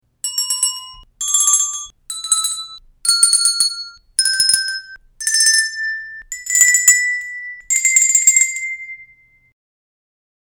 Glocken I
Einfach aus Spaß drauflos klingeln oder die Tonleiter spielen - mit den Glocken können sogar ganze Lieder nachgespielt werden.
• Material: Metall, Kunststoff